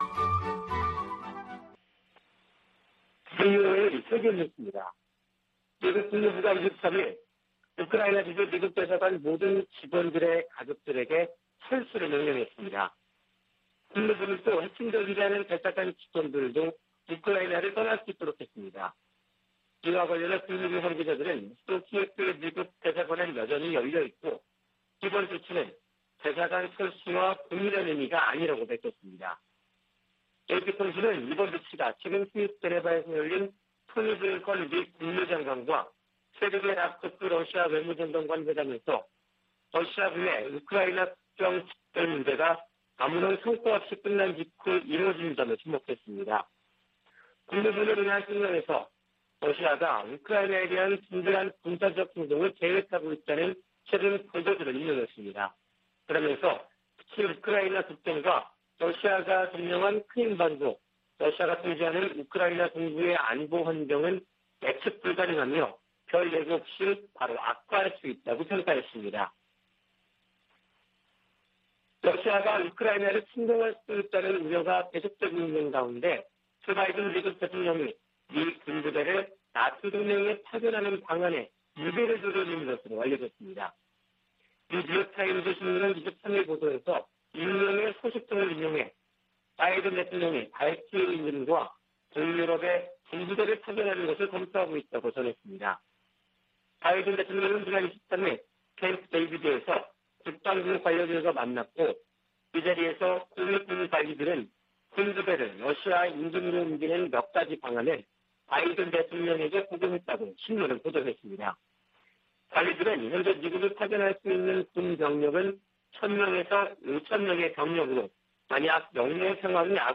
VOA 한국어 아침 뉴스 프로그램 '워싱턴 뉴스 광장' 2021년 1월 25일 방송입니다. 미-일 화상 정상 회담에서 북한의 잇따른 탄도미사일 시험 발사를 규탄하고 긴밀한 공조를 다짐했습니다. 미 국무부는 핵과 대륙간탄도미사일 실험 재개 의지로 해석된 북한의 최근 발표와 관련해 외교와 압박을 병행하겠다는 원칙을 확인했습니다. 미 국방부는 북한의 무기실험 재개 시사에 우려를 나타내면서도 외교적 관여 기조에는 변화가 없다고 밝혔습니다.